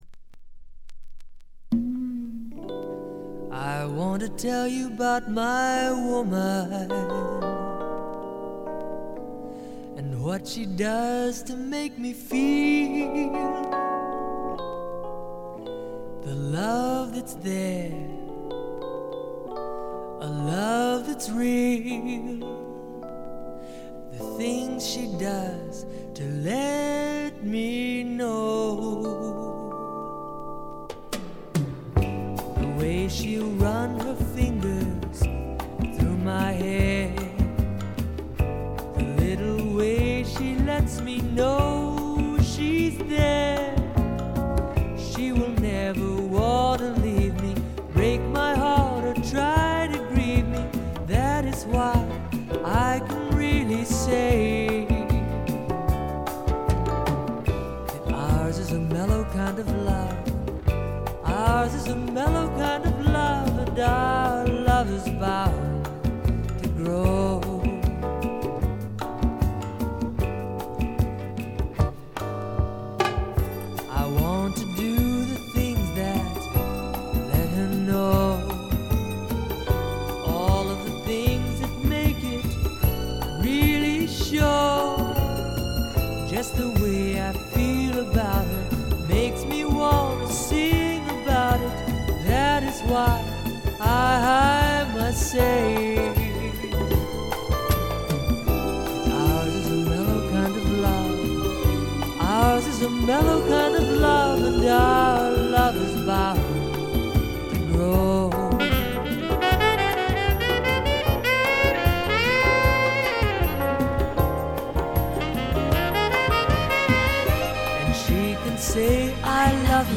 部分試聴ですが、ほとんどノイズ感無し。
海を渡ってくる涼風が最高に心地よいです。
海洋系AORの傑作／大名盤！
試聴曲は現品からの取り込み音源です。